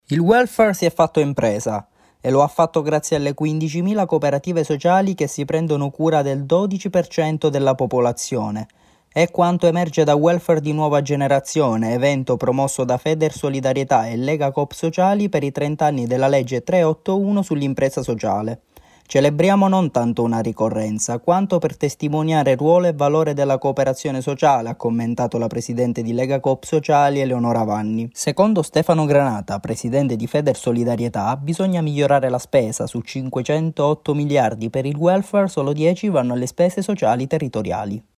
Servizio-impresa-sociale-Grs.mp3